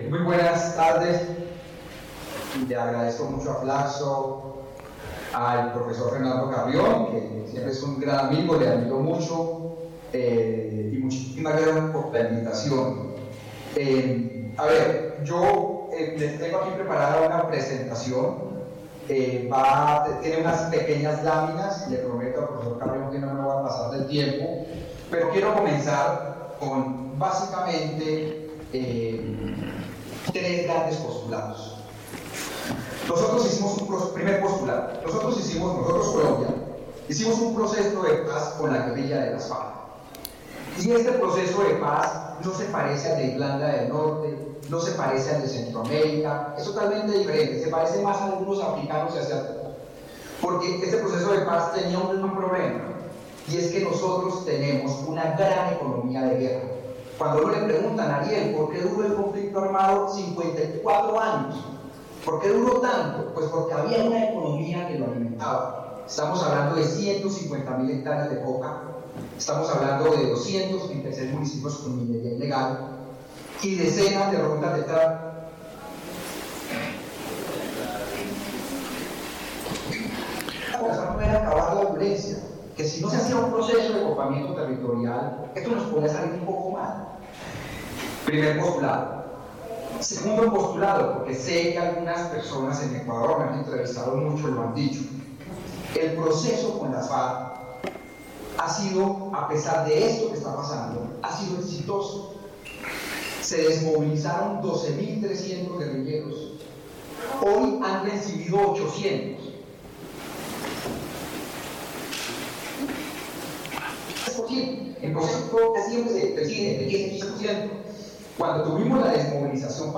Conversatorio